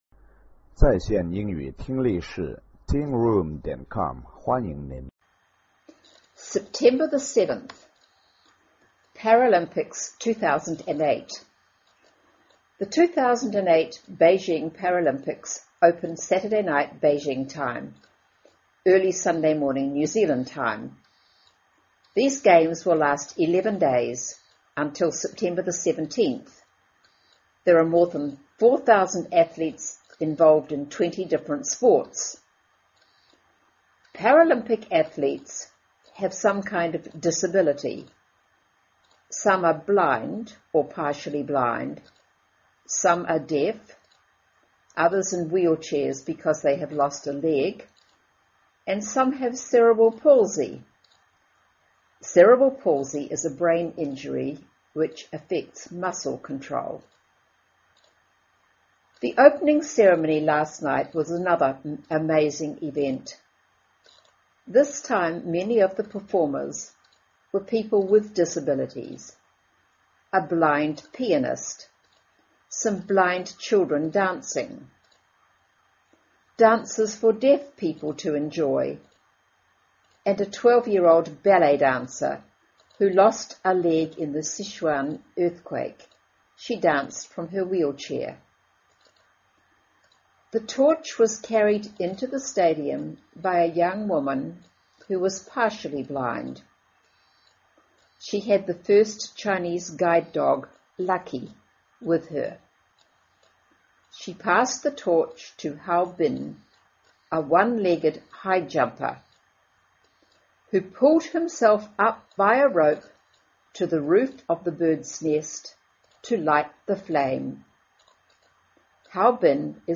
新西兰英语 56 Paralympics 2008 听力文件下载—在线英语听力室